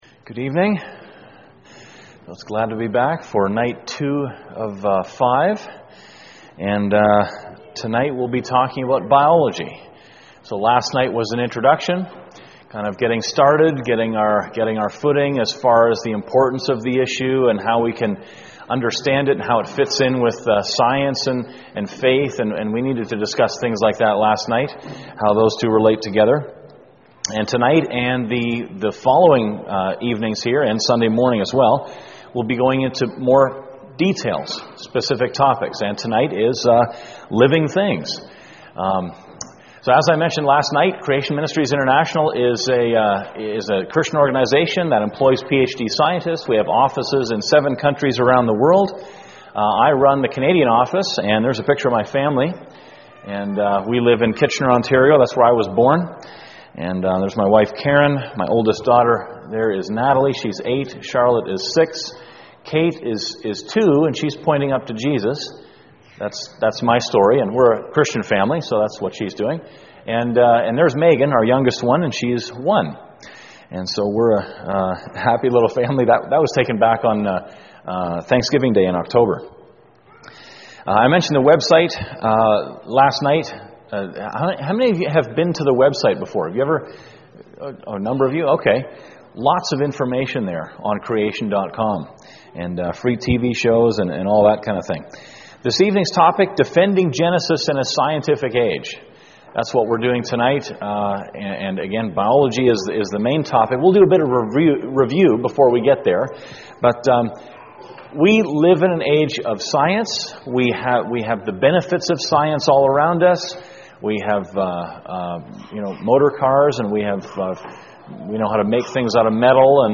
Series: Creation Seminar - March 2011 Service Type: Thursday Evening %todo_render% « Creation/Evolution